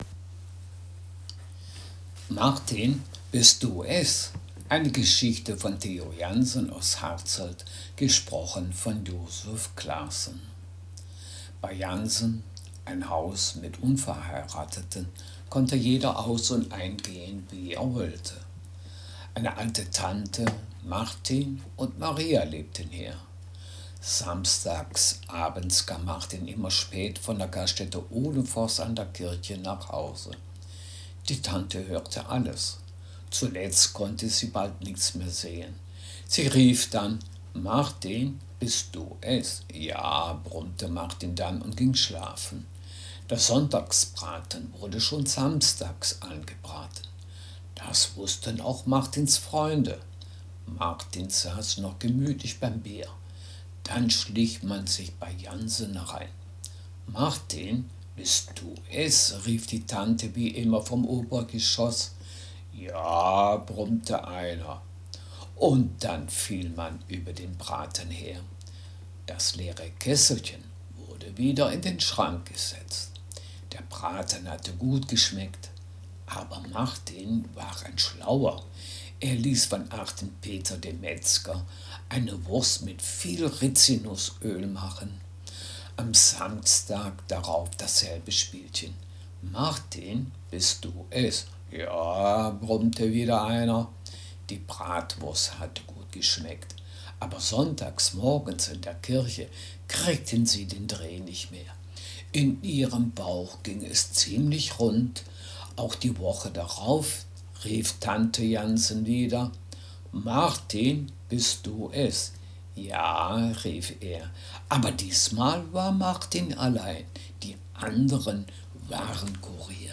Text hochdeutsch
Geschichte